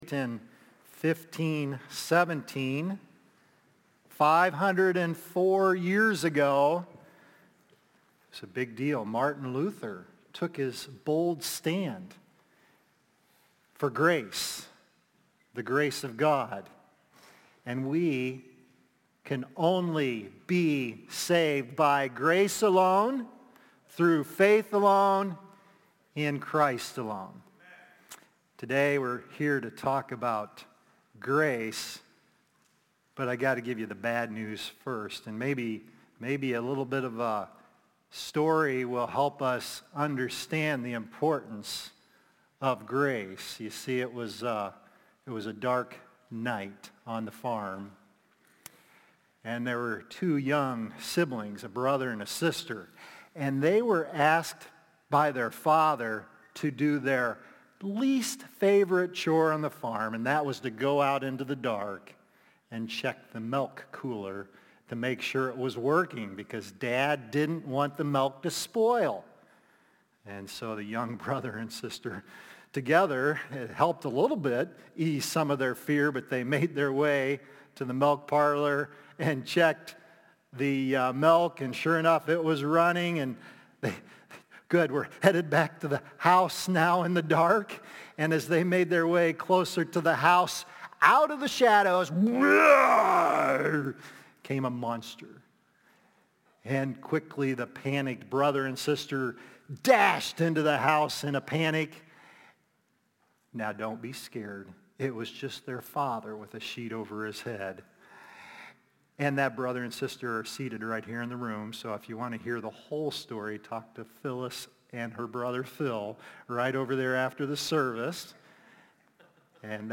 Sin Grows Until Grace Comes | Baptist Church in Jamestown, Ohio, dedicated to a spirit of unity, prayer, and spiritual growth